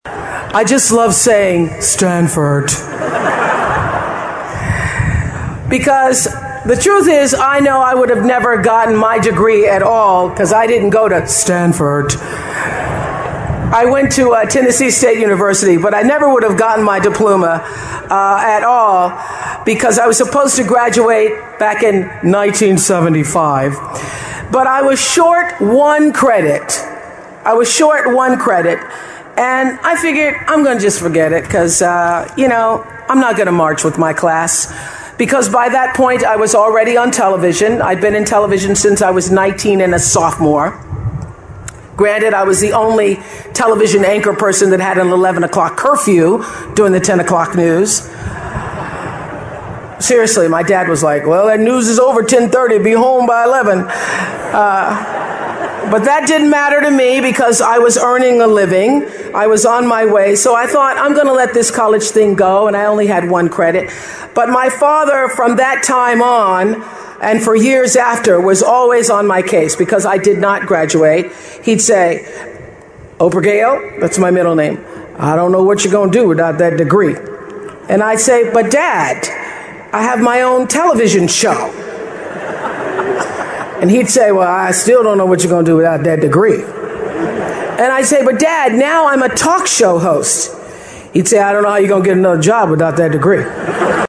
名人励志英语演讲 第141期:感觉失败及寻找幸福(3) 听力文件下载—在线英语听力室